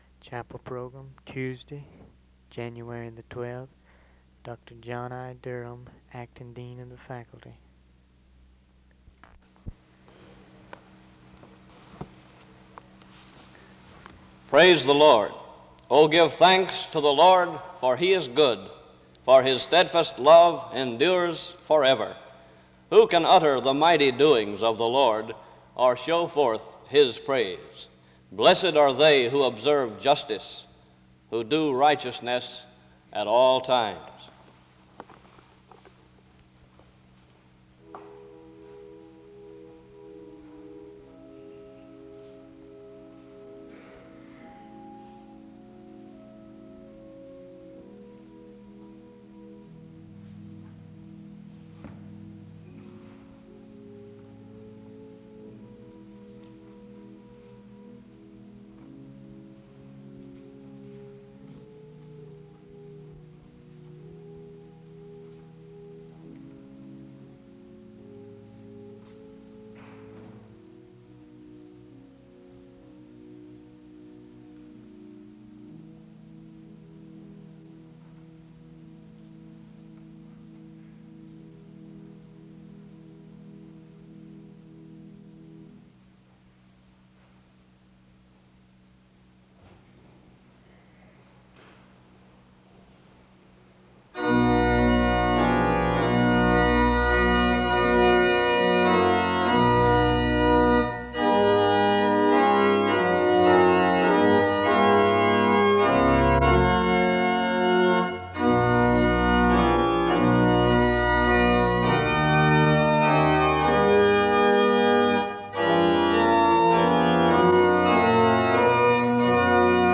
The service begins with scripture reading and music from 0:00-4:37. A prayer is offered from 4:44-7:15. Psalm 139 is text for the responsive reading. The scripture is read from 7:19-9:00.